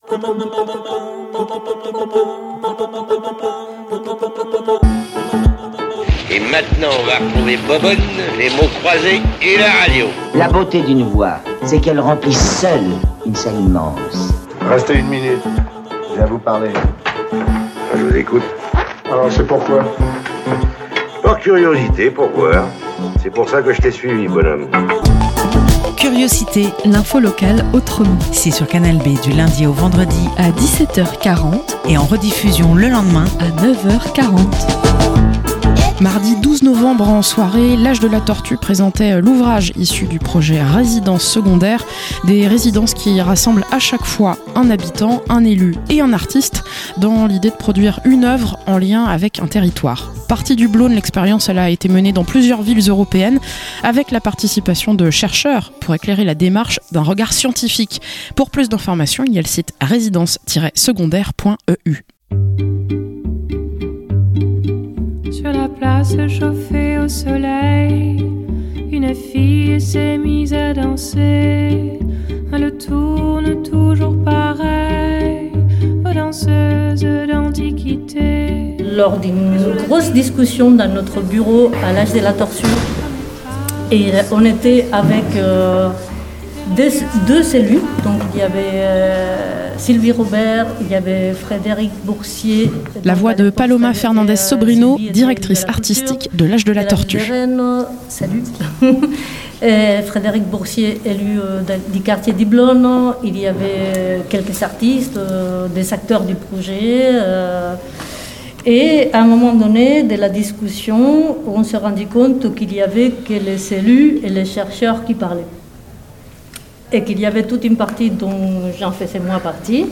- Reportage lors du vernissage de l'ouvrage issu de "Résidence secondaire", un projet européen de résidence entre artistes, élus et habitants mené par l'Age de la tortue.